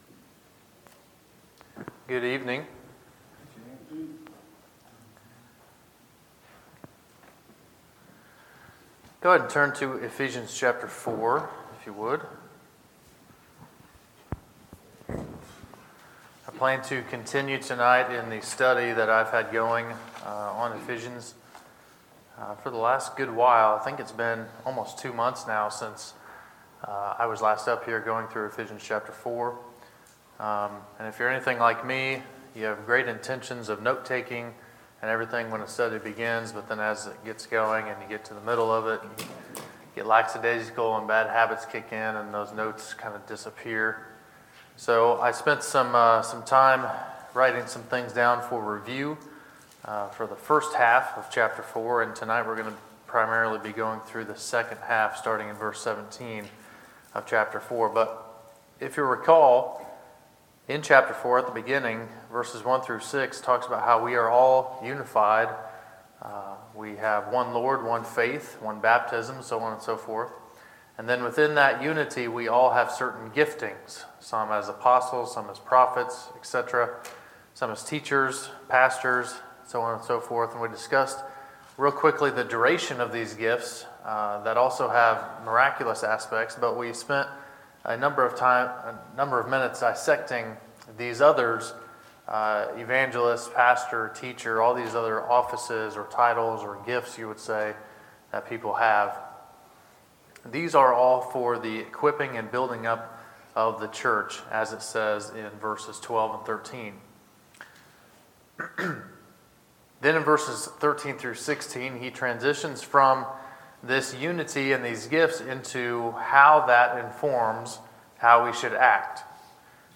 Sermons, January 7, 2018